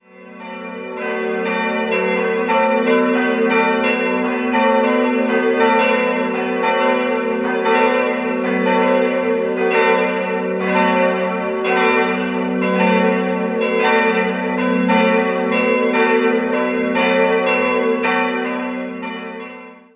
3-stimmiges TeDeum-Geläute: fis'-a'-h' Die beiden größeren Glocken wurden 1952 von Friedrich Wilhelm Schilling in Heidelberg gegossen. Die kleine stammt aus dem Jahr 1702 von den Gebrüdern Arnoldt aus Dinkelsbühl.